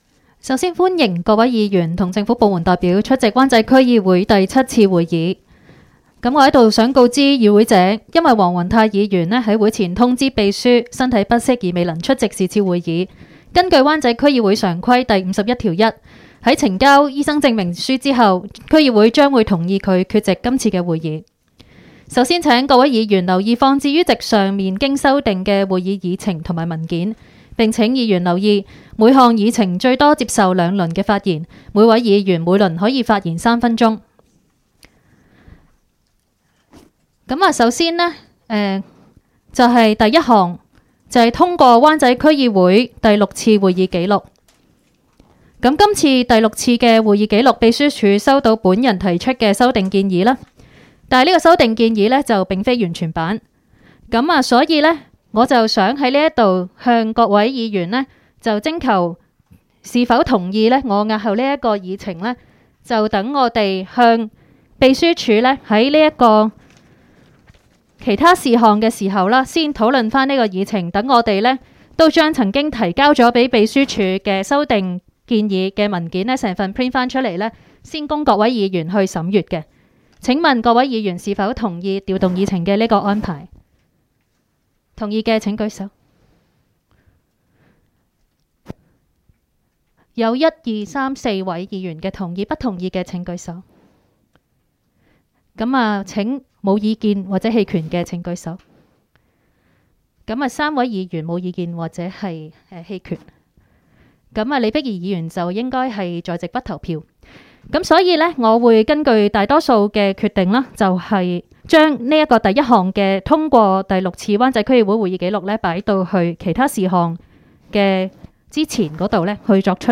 湾仔区议会会议室